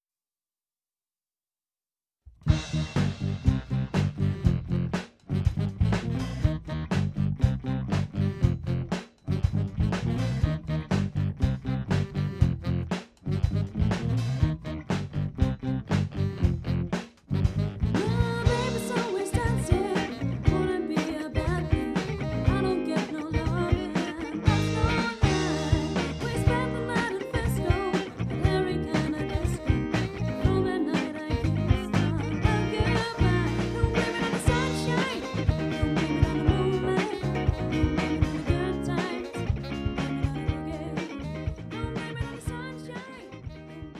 • Coverband
• Soul/Funk/Groove